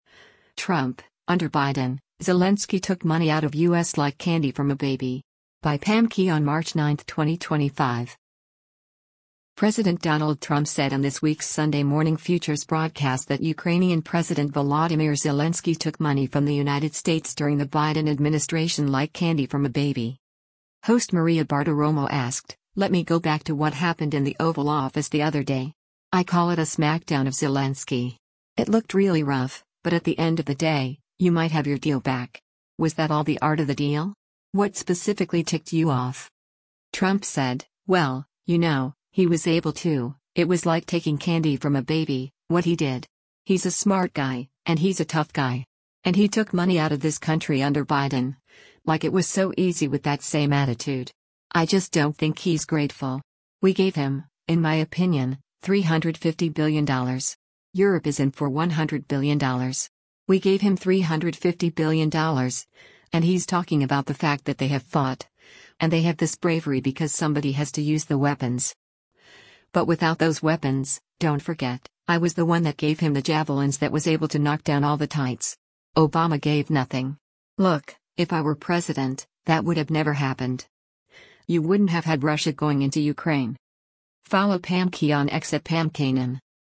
President Donald Trump said on this week’s “Sunday Morning Futures” broadcast that Ukrainian President Volodymyr Zelensky took money from the United States during the Biden administration “like candy from a baby.”